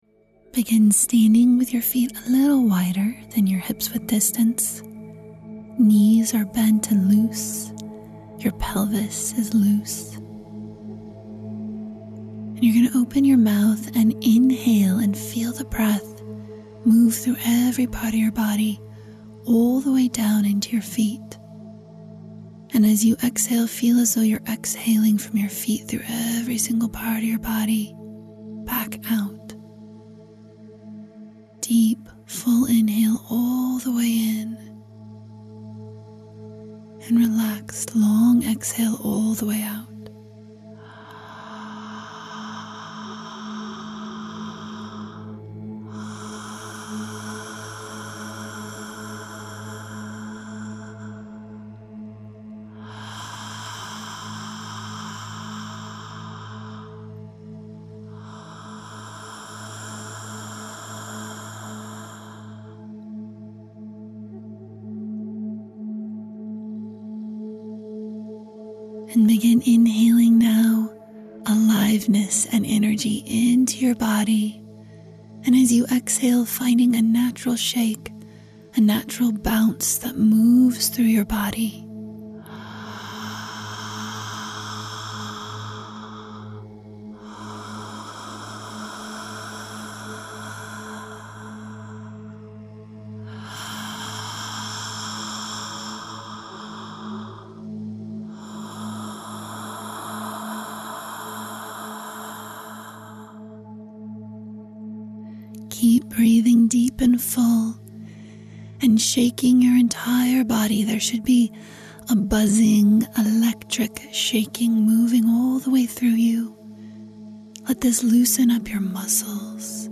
Wild Woman Practices Audio File - Three downloadable MP3 of guided practices to support your awakening Wild Woman!